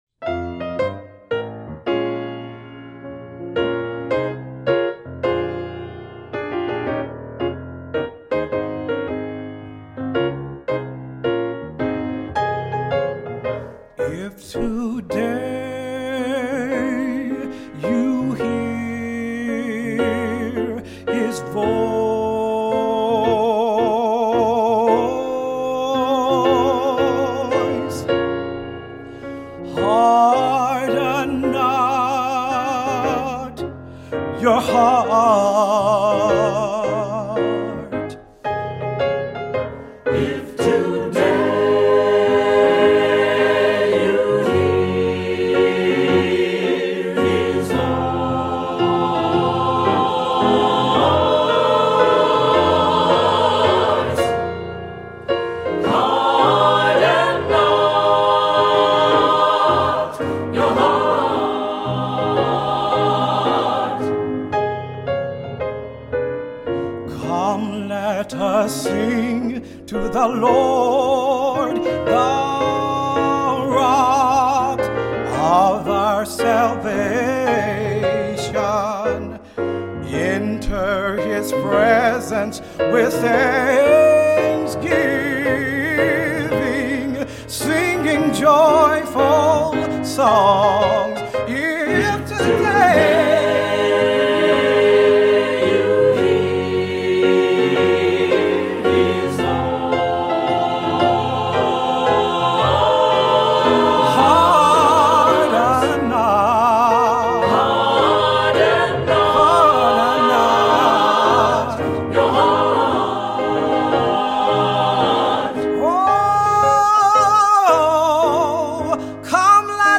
Voicing: Cantor; Assembly